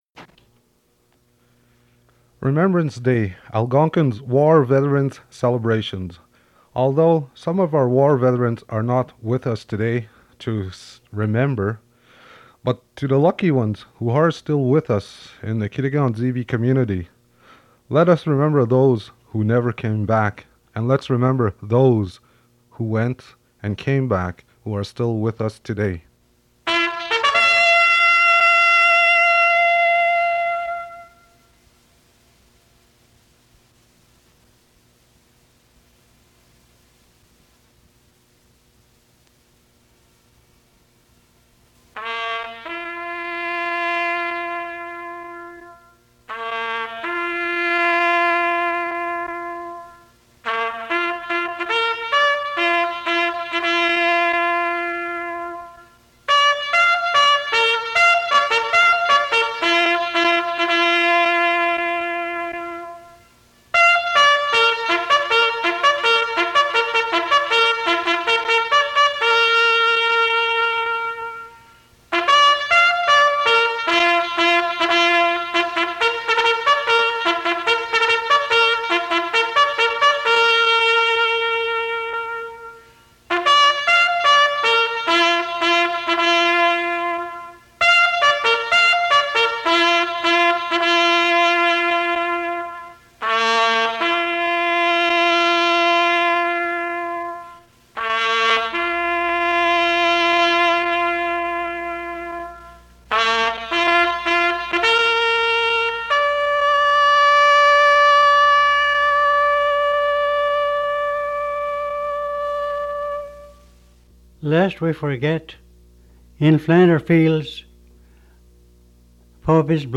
Fait partie de Remembrance Day broadcast